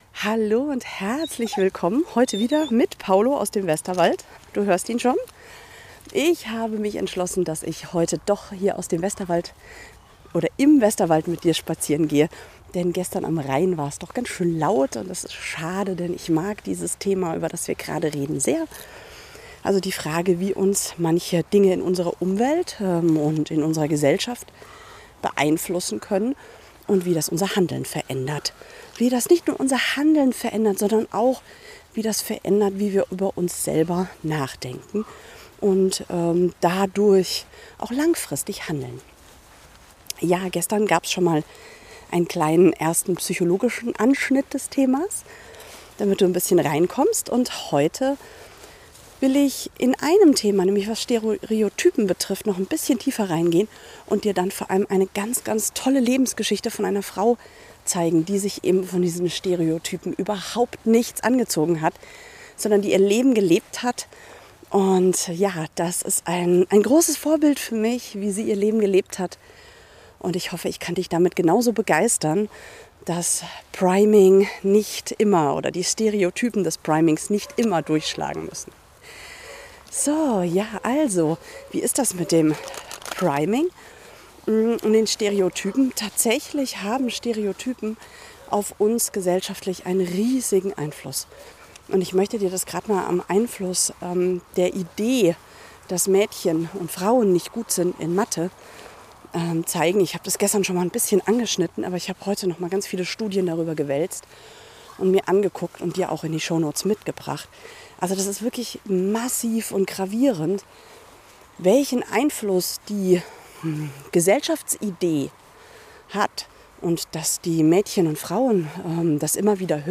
Westerwald unterwegs, weil gestern am Rhein einfach zu viel los war